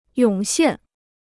涌现 (yǒng xiàn): to emerge in large numbers; to spring up.